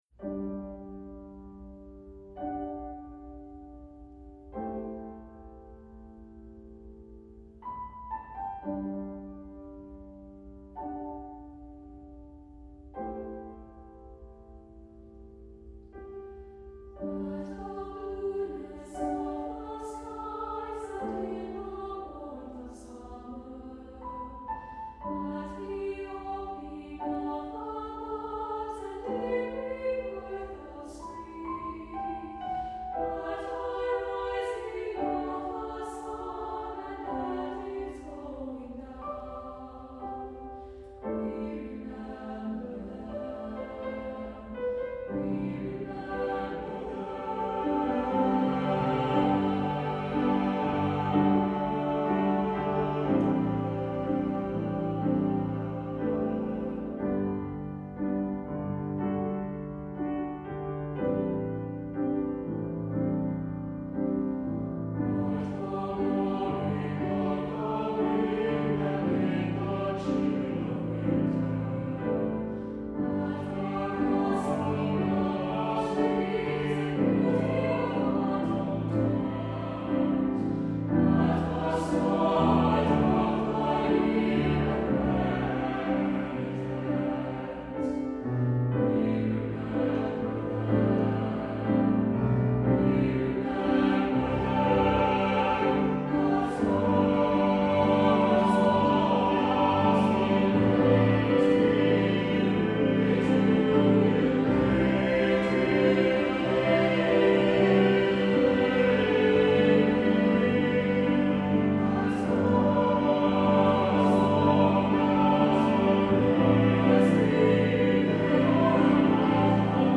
Accompaniment:      Piano
Music Category:      Choral
highly melodic and touching song
for SATB chorus and piano